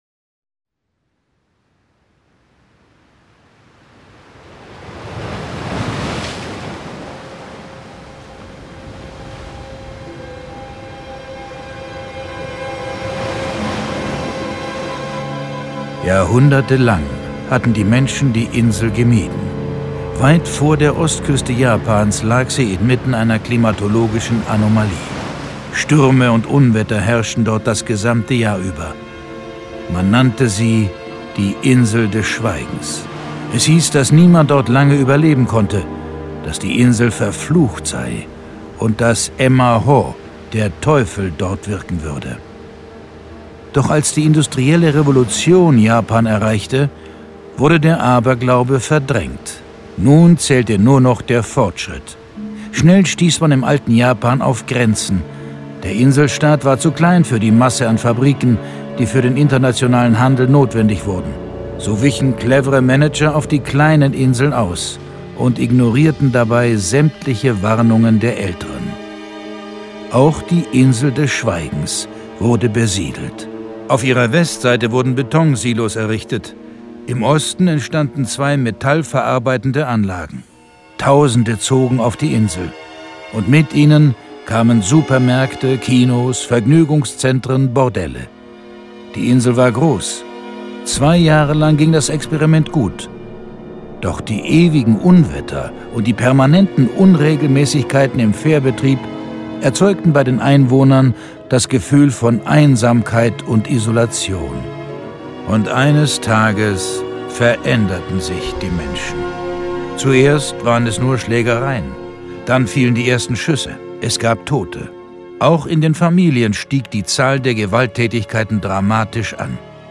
John Sinclair - Folge 63 Tokatas Todesspur. Hörspiel.